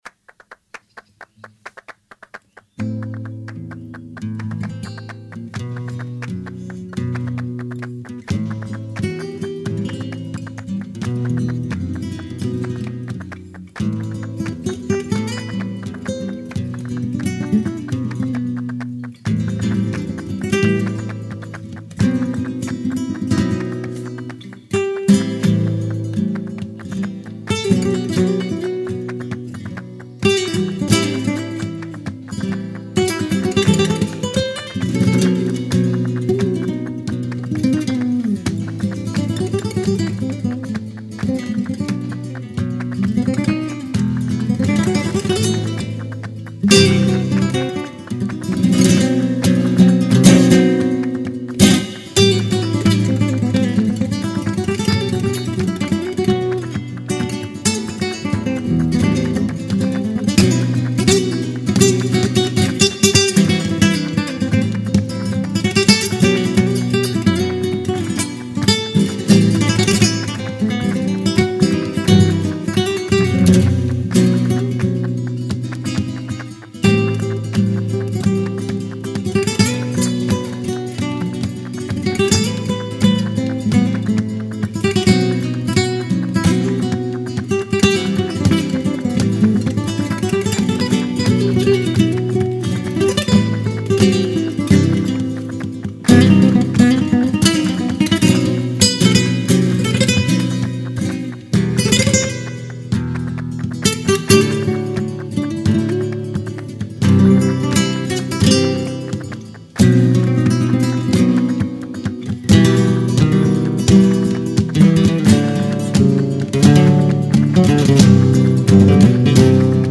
Bulería